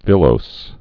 (vĭlōs)